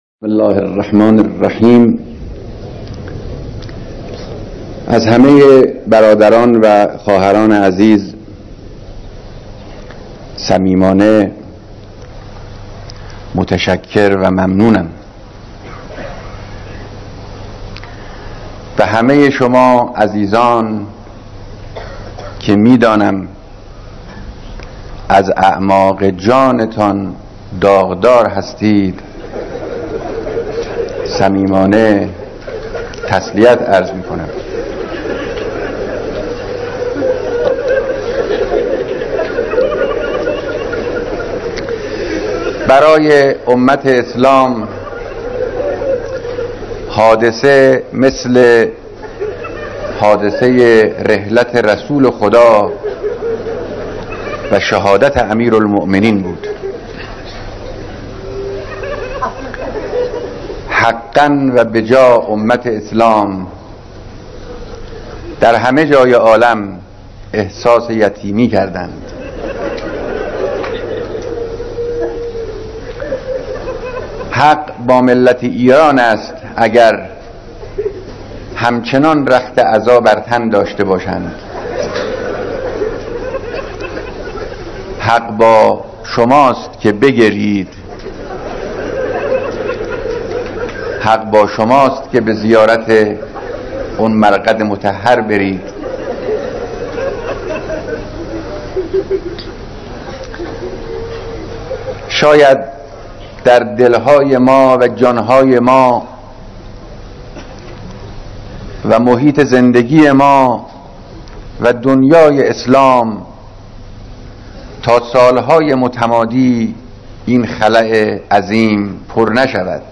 بیانات در مراسم بیعت مسئولان نهضت سواد آموزی